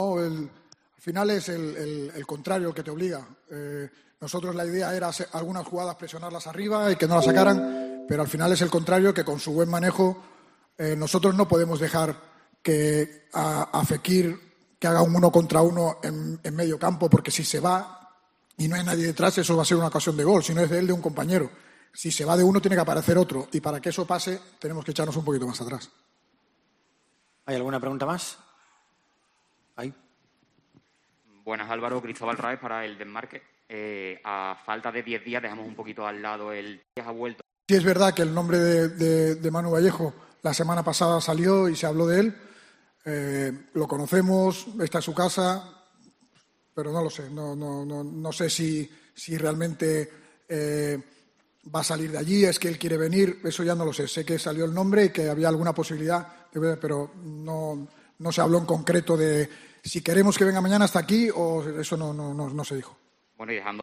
El análisis de Álvaro Cervera tras el Betis 1-1 Cádiz